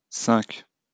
wymowa:
IPA[sɛ̃k] (zob. uwagi) ?/i